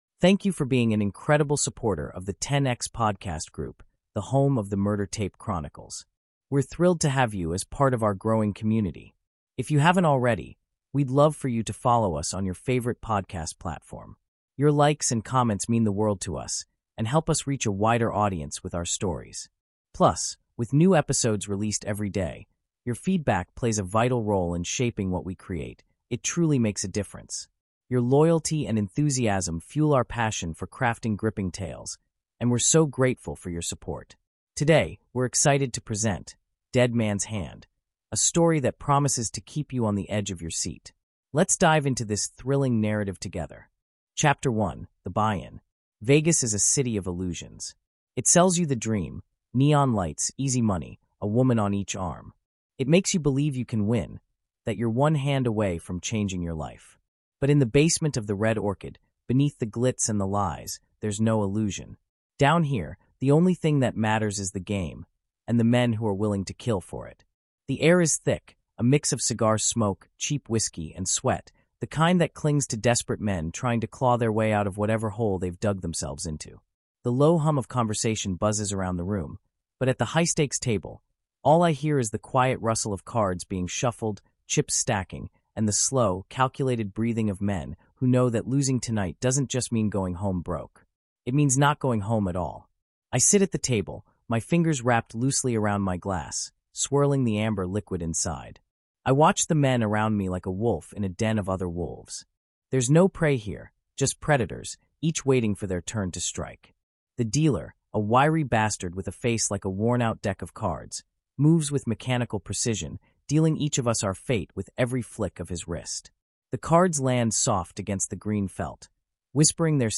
Dead Man’s Hand | Audiobook